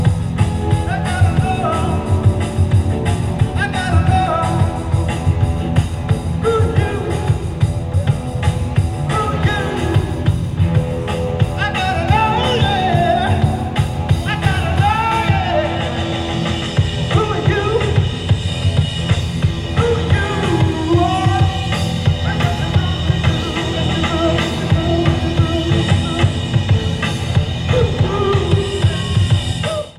Format/Rating/Source: CD - C - Audience
Comments: OK audience recording.